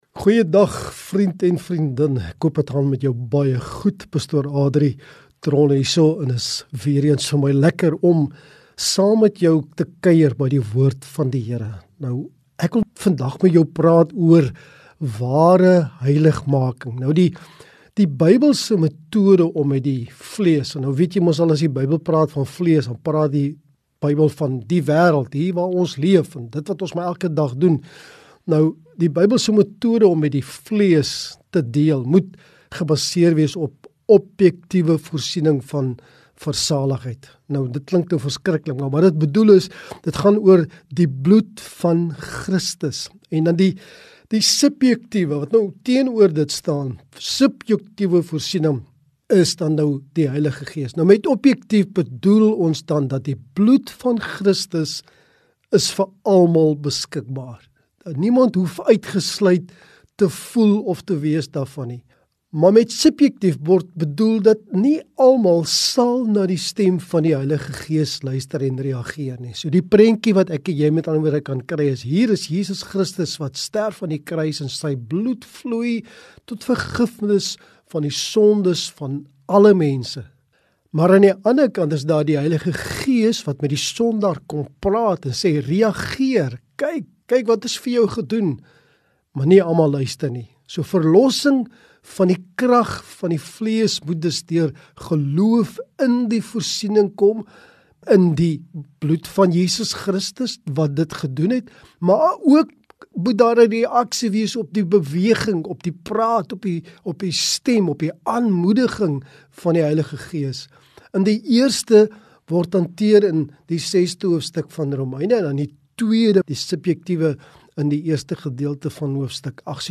Word geïnspireer deur Tygerberg 104fm se daaglikse boodskappe, aangebied deur verskeie predikers van verskillende denominasies. Die Aandboodskap word elke aand, van Maandag tot Donderdag, en op Sondagaande om 19:30, en die Sondagoggend boodskap om 10:00 uitgesaai op Tygerberg 104fm.